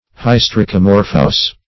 Search Result for " hystricomorphous" : The Collaborative International Dictionary of English v.0.48: Hystricomorphous \Hys`tri*co*mor"phous\ (h[i^]s`tr[i^]*k[-o]*m[^o]r"f[u^]s), a. [Hystrix + Gr. morfh` form.]